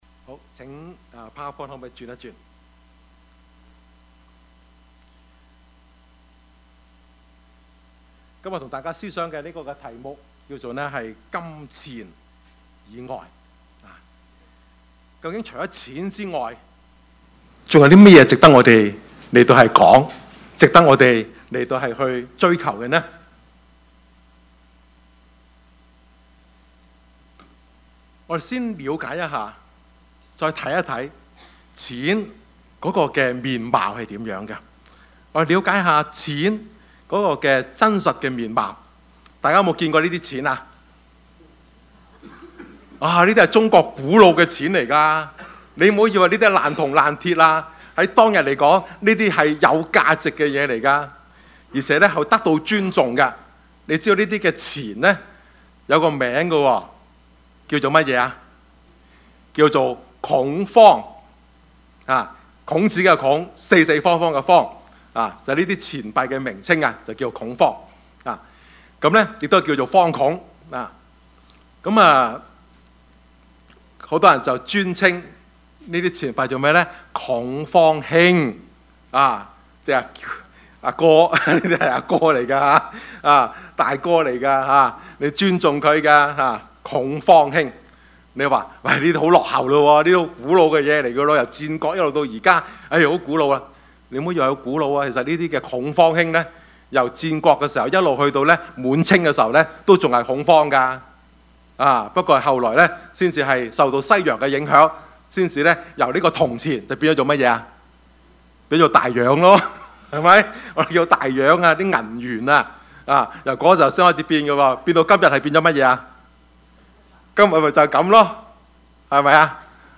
Service Type: 主日崇拜
Topics: 主日證道 « 彩虹之約 與神同行 »